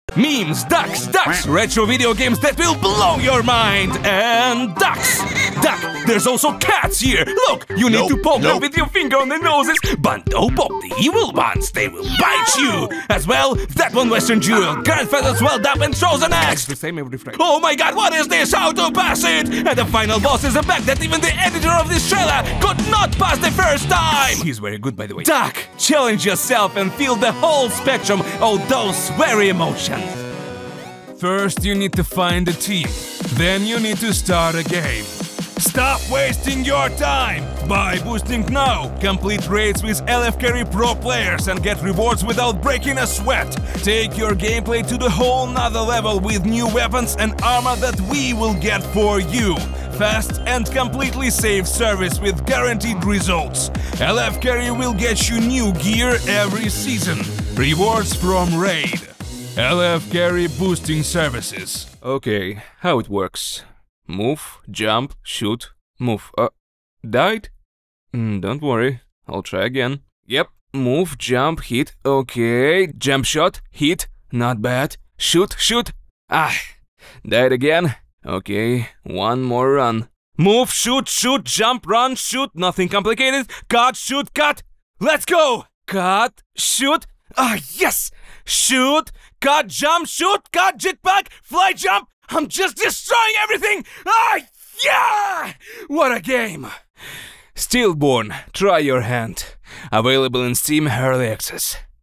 Пример звучания голоса
Реклама Демо ENG
Муж, Рекламный ролик/Молодой